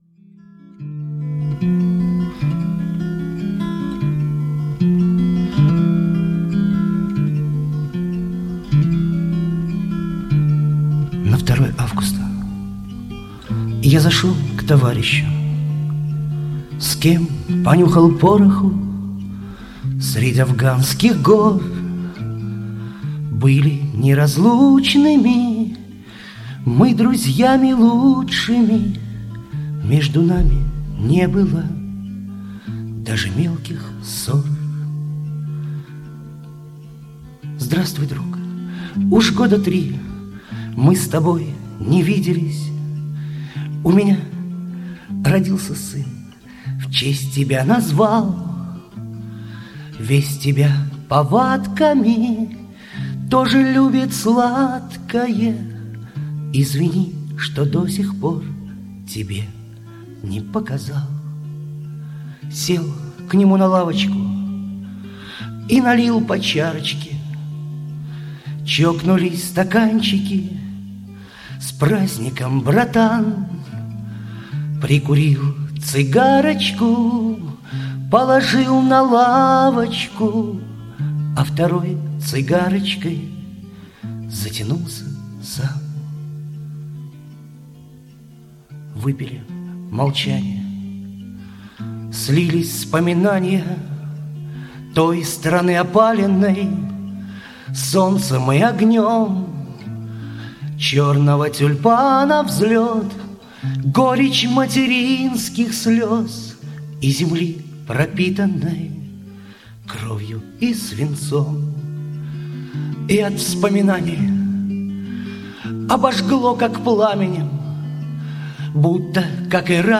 Гитара / армейские